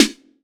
6SNARE 1.wav